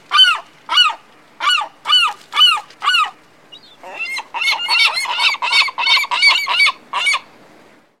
seagull-sounds